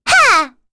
Rehartna-Vox_Attack1.wav